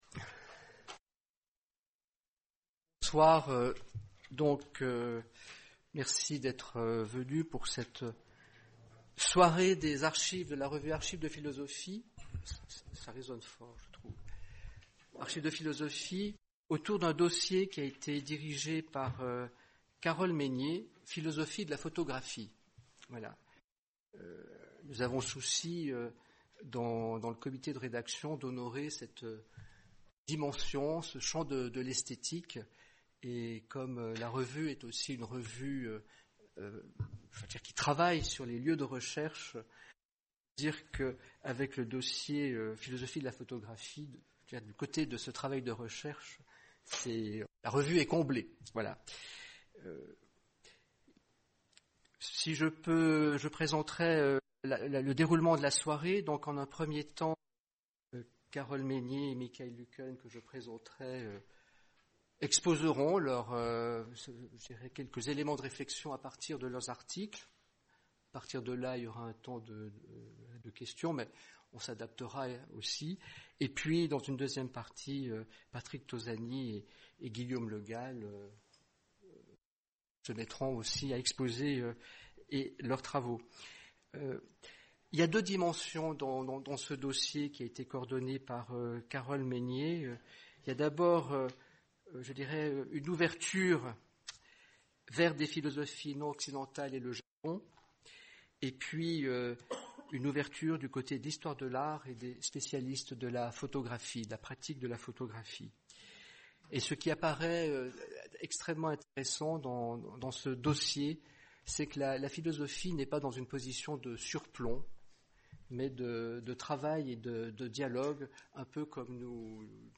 Présentation de la manière originaire dont la philosophie se réalise en travaillant, encore et toujours, la question du temps et de l’instant, dès lors qu’elle prend acte de l’irruption de l’image photographique dans la vie de la pensée et de ses effets sur cette vie. Une rencontre pour débattre autour d’une nouvelle figuration de la philosophie.